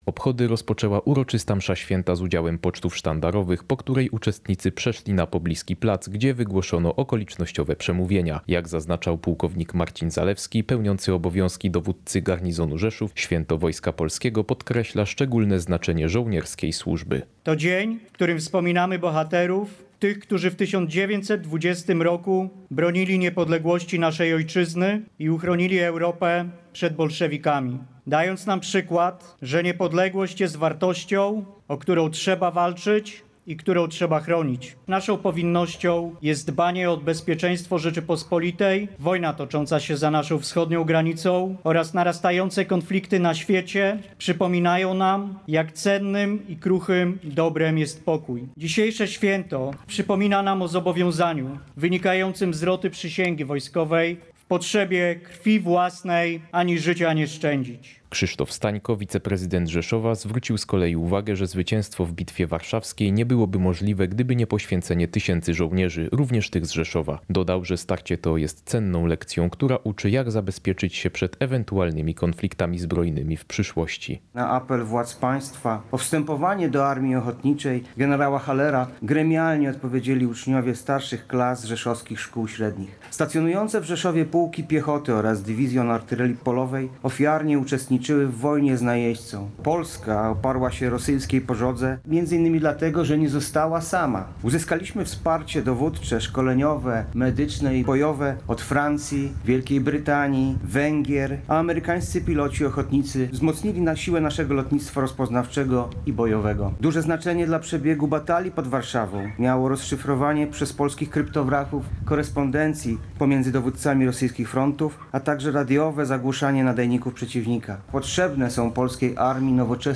Uroczystości odbyły się przy Kościele Garnizonowym w Rzeszowie.
Po uroczystej mszy świętej z udziałem pocztów sztandarowych wygłoszono okolicznościowe przemówienia.
Podczas uroczystości części mundurowych wręczono odznaczenia i awanse na wyższe stopnie wojskowe, po czym wysłuchano apelu pamięci i oddano salwę honorową.